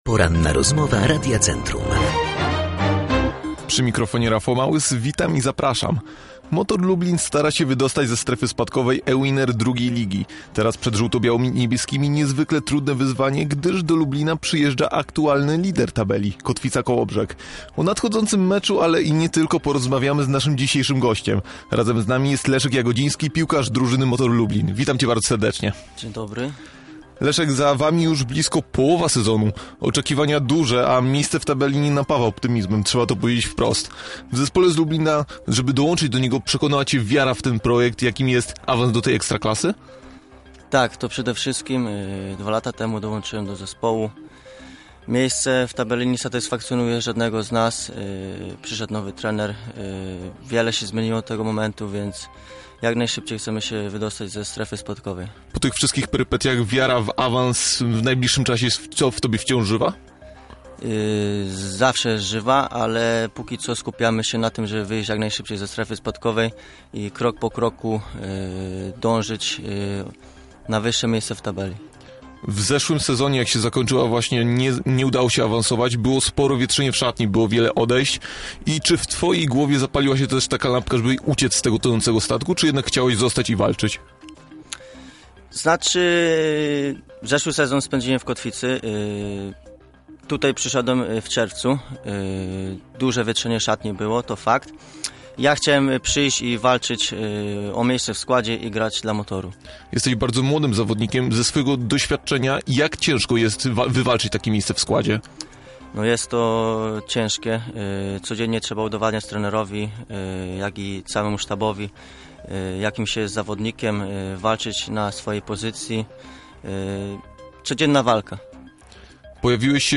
Rozmowa po edycji